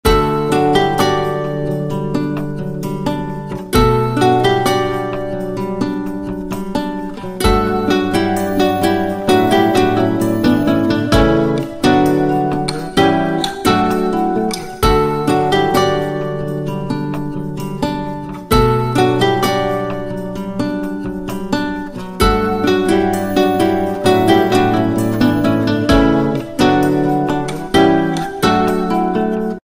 الجيتار